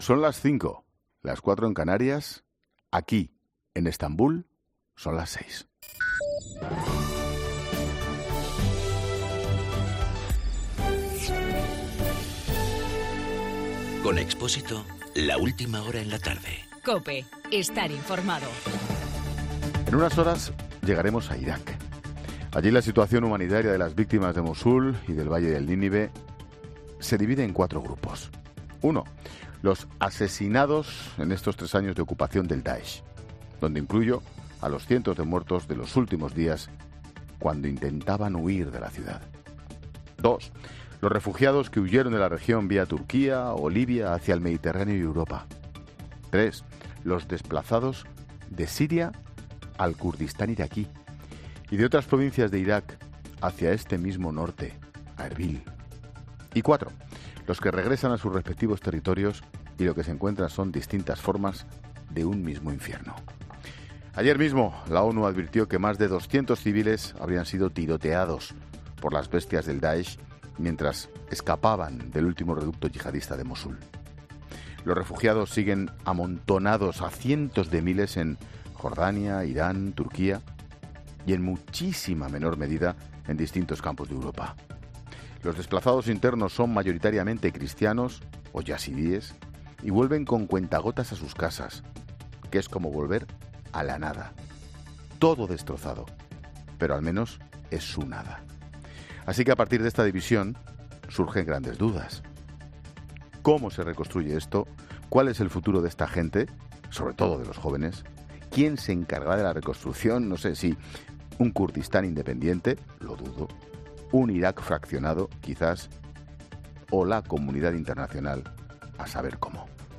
Monólogo de Expósito
El monólogo de Ángel Expósito de las 17h desde Estabul de camnino a Mosul, citando las muertes provocadas por el Daesh y el número de refugiados y de desplazados.